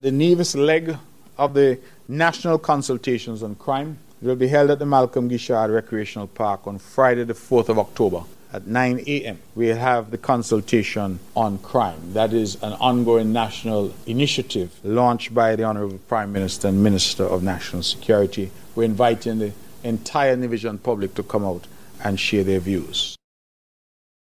The Nevis edition of the National Consultation on crime and violence will be held this week. Premier, the Hon. Mark Brantley, provided these details.